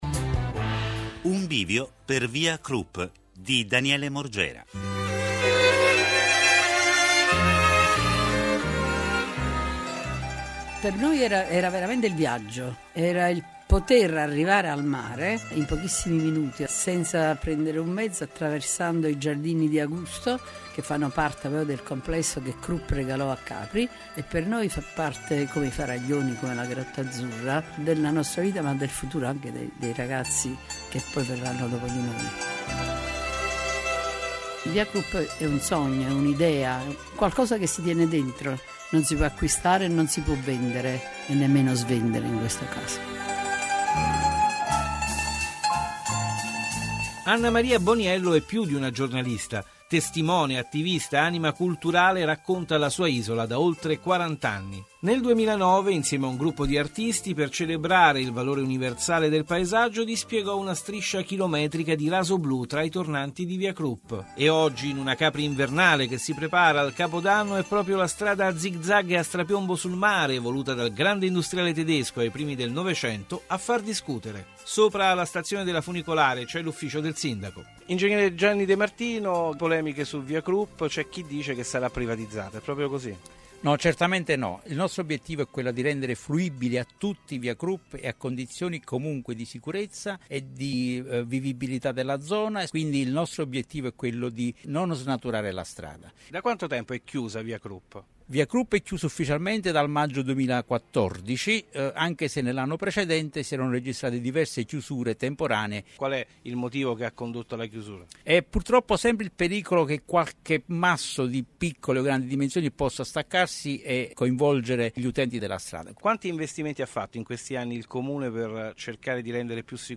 Intervistati (in ordine di scaletta)